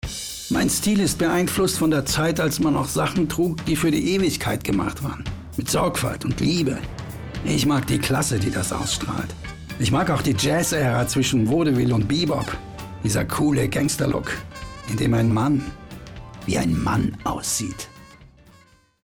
sehr variabel
Mittel plus (35-65)
Presentation